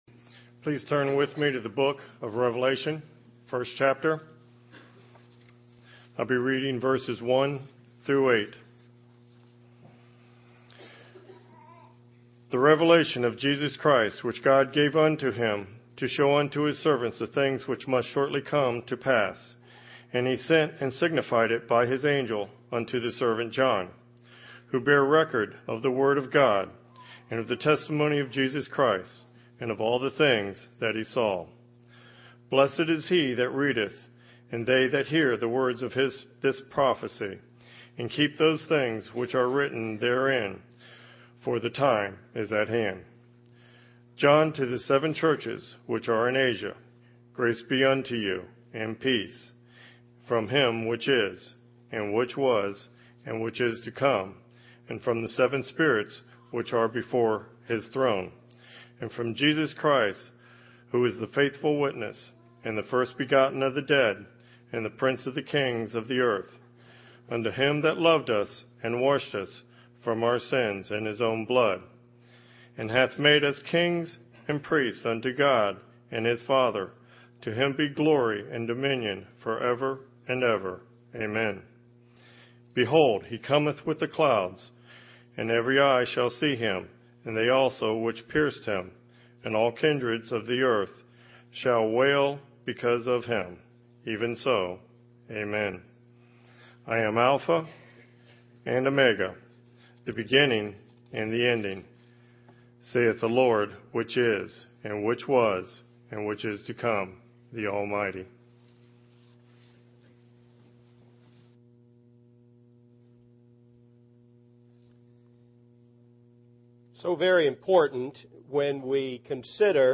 Brown Street church of Christ Sermons
Book, Chapter, Verse gospel preaching.